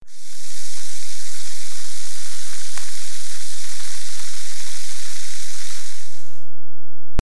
Soda Fizz (WAV file)
48k 24bit Stereo
Relevant for: soft, bubbles, tonic, carbonate, carbonation, bar, bartender.
Try preview above (pink tone added for copyright).